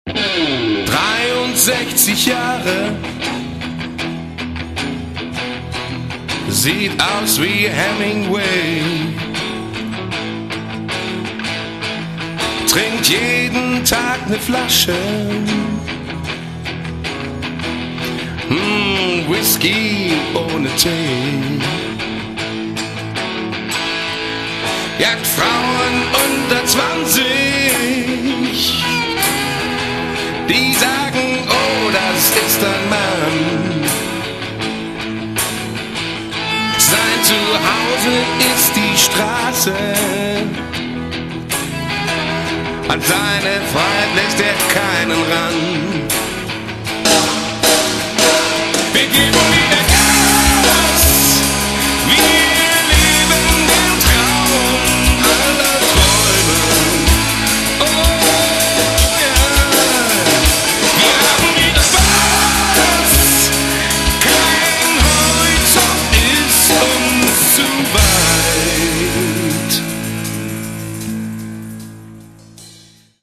Git.und Voc.
Dr., Chor, Bongolein, klappern & rascheln
aufgenommen, gemischt und produziert